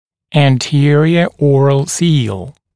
[æn’tɪərɪə ‘ɔːrəl siːl][эн’тиэриэ ‘о:рэл си:л]герметичное ротовое перекрытие вестибулярного отдела